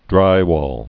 (drīwôl)